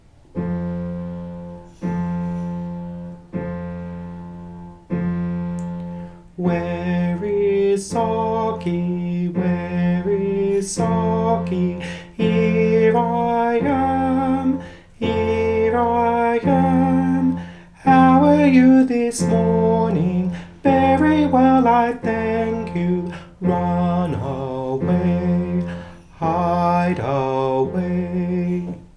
' This is to the tune of Fere Jaques WHAT TO DO: The child and the adult have their Socky puppets on.